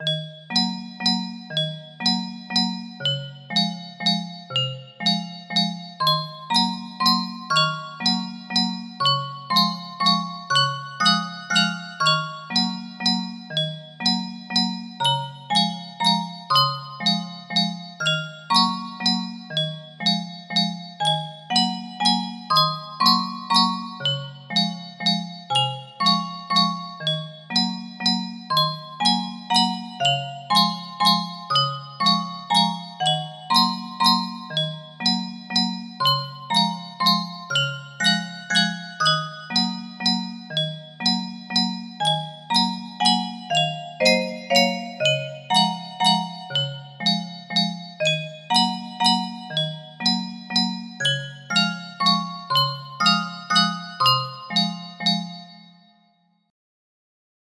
CC Melody music box melody
Full range 60
Based on the circus melodies